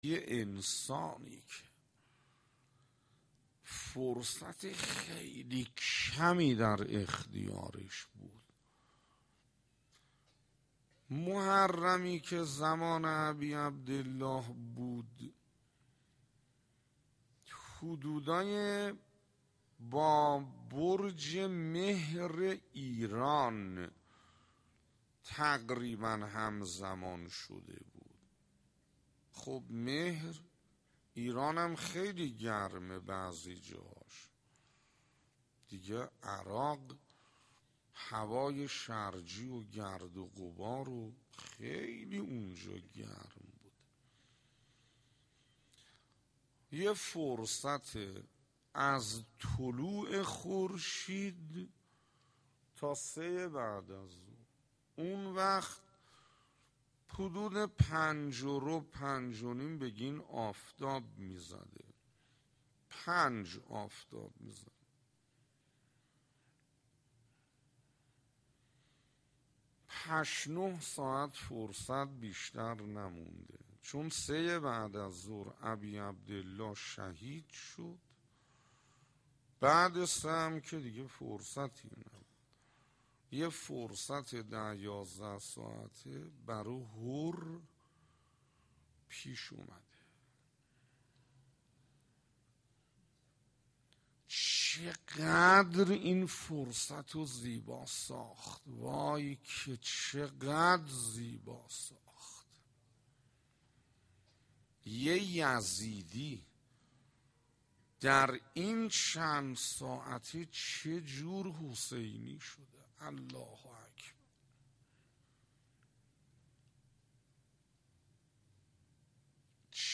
روضه حر - 1
روضه حر - 1 خطیب: استاد حسين انصاريان مدت زمان: 00:07:23